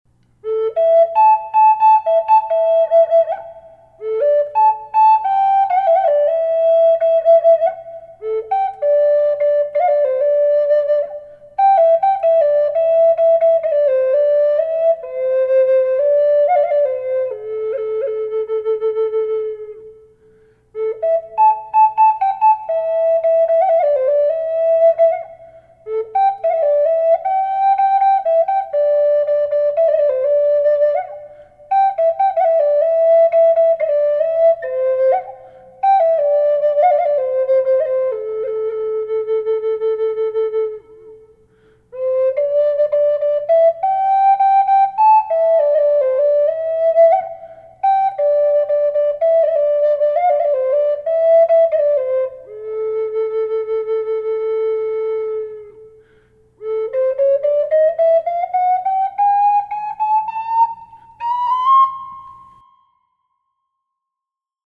A minor old growth Western Red Cedar Flute.
Gorgeous voice & tone.
Sound Sample with a light reverb
A-cedar-reverb-oldgrowth-01.mp3